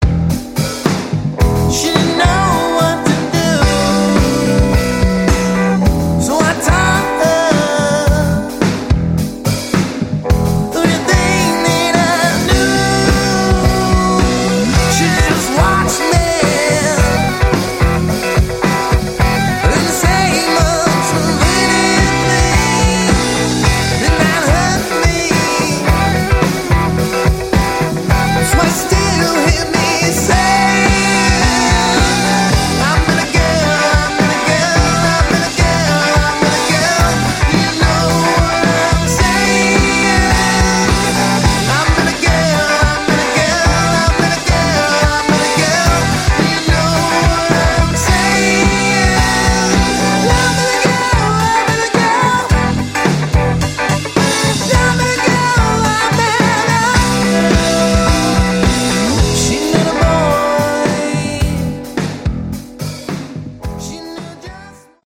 Category: Hard Rock
lead vocals, bass
guitars, keyboards, backing vocals
drums